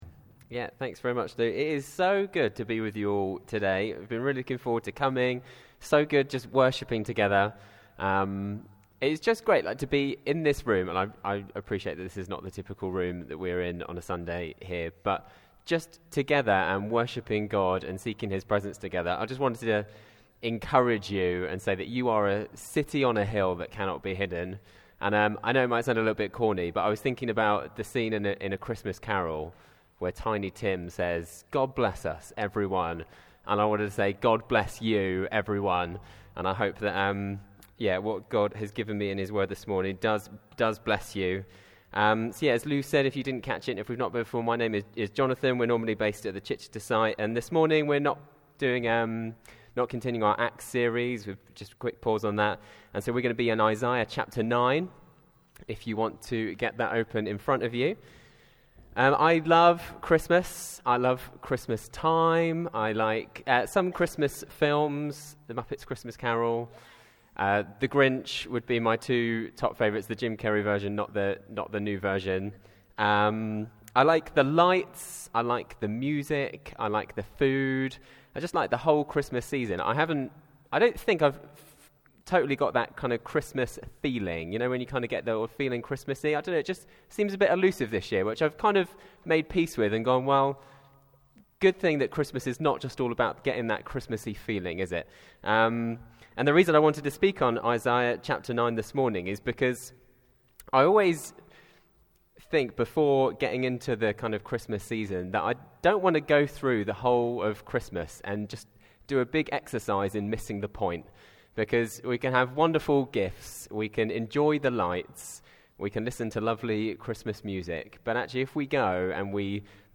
Series: Other Sermons 2025